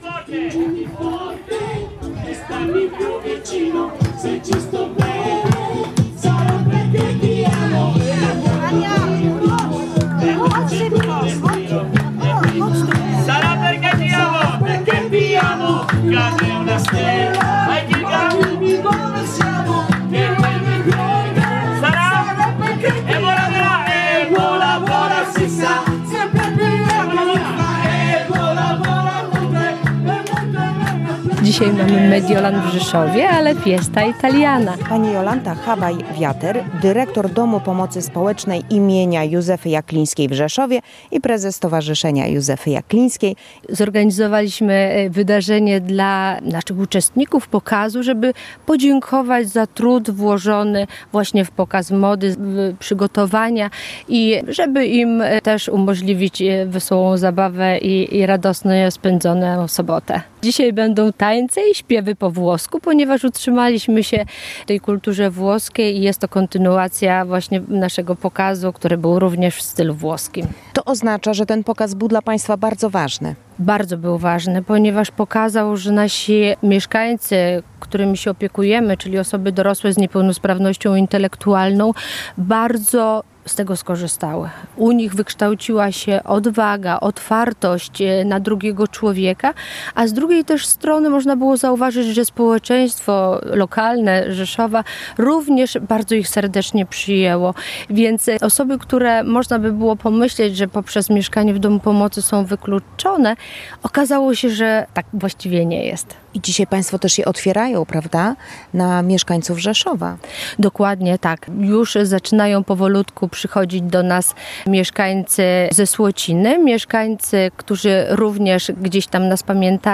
Mieszkańcy i przyjaciele Domu Pomocy Społecznej im. Józefy Jaklińskiej w Rzeszowie spotkali się na pikniku w stylu włoskim.
W ogrodzie ustawione zostały namioty, przygotowano włoskie smakołyki, była muzyka i tańce. Uczestnicy wspominali zorganizowany przez Stowarzyszenie Józefy Jaklińskiej czerwcowy Pokaz Mody i dziękowali za zaangażowanie.